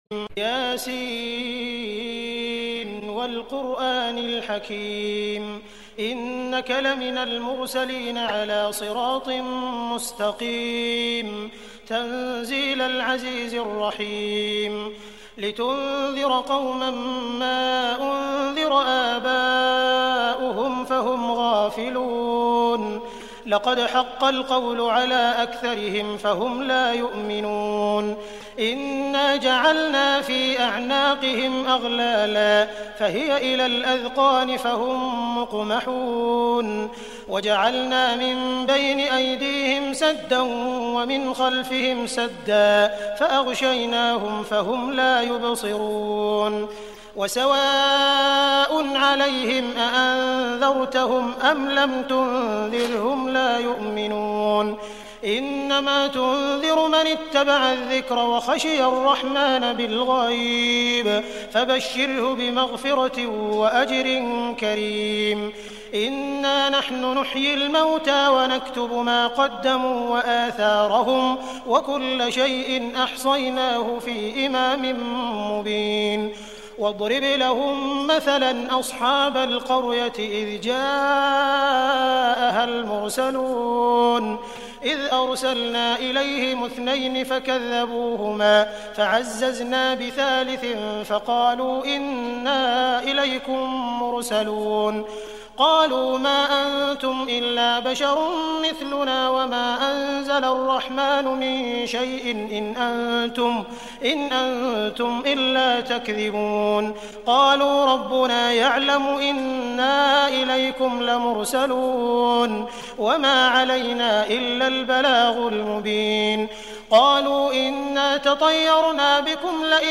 surah Yasin sheikh sudais reading the Quran
emotional Quranic recitation